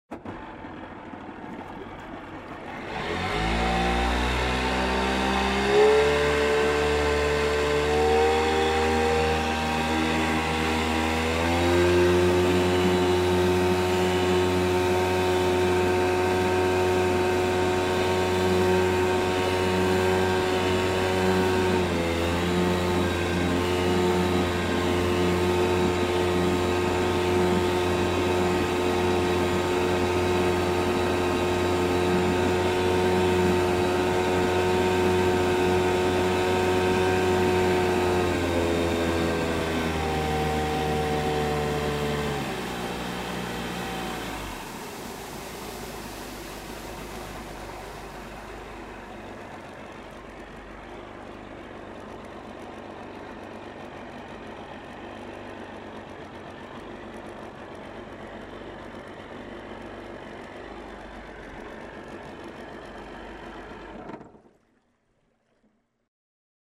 Звуки гидроцикла
Здесь вы найдете рев двигателей, шум волн, разрезаемых корпусом, и другие атмосферные эффекты.